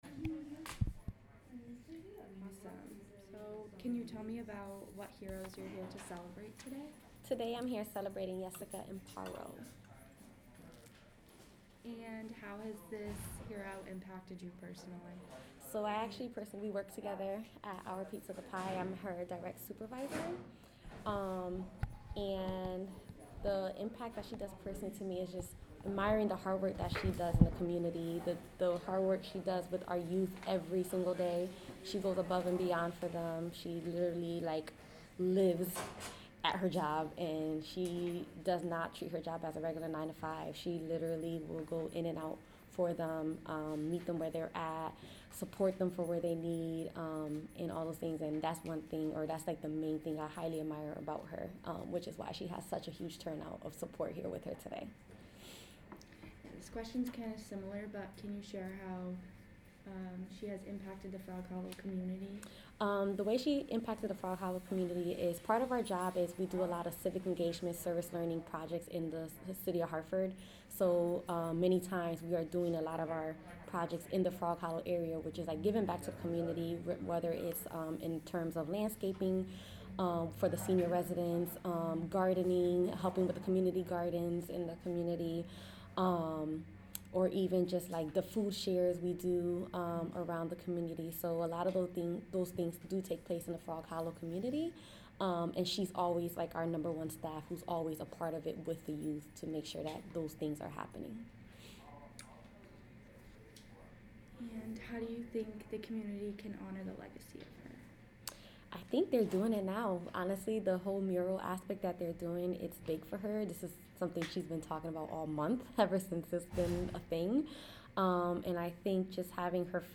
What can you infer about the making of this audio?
The Park Street Library @ the Lyric